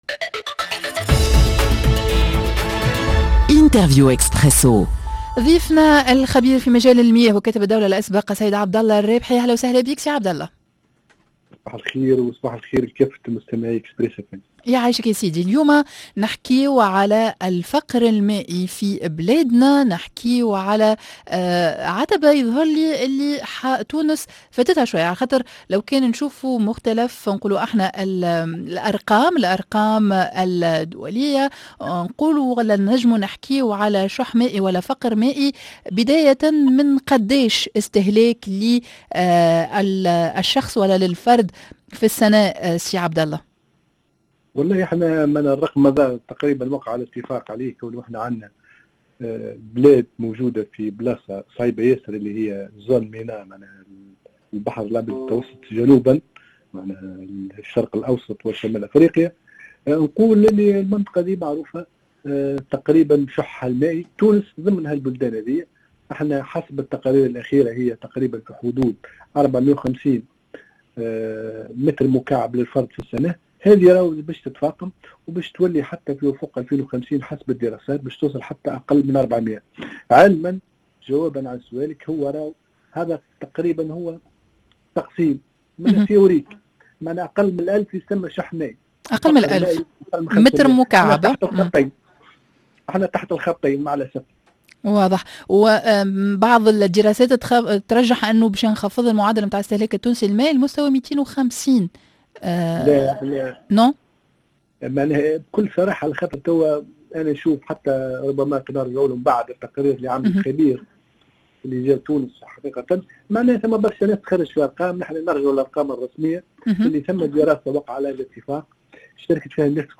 نقص كبير في احترام الحق في الماء في تونس ، وهي على خط الفقر المائي،ضد ضيفنا الخبير في مجال المياه وكاتب الدولة الأسبق عبد الله الرابحي